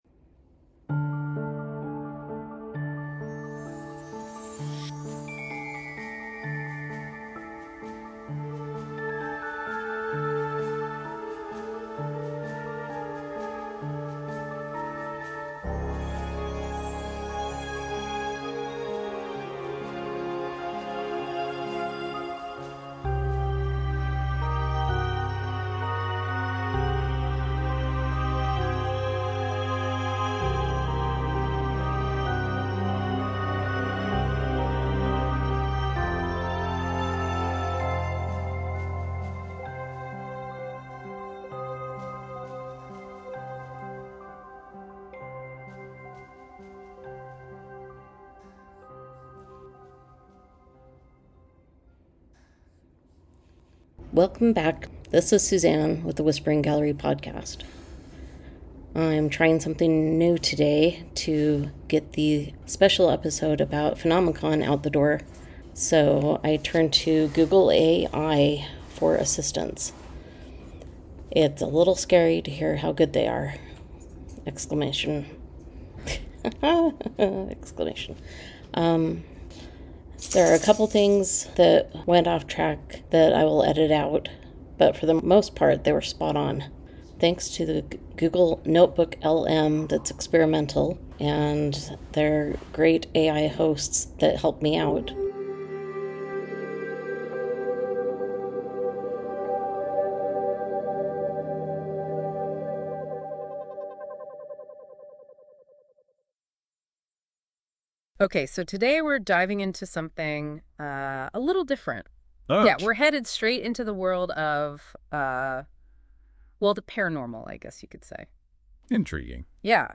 The PhenomeCon episode was condensed down to 8 minutes, presented in podcast form, with a discussion between two unnamed AI hosts.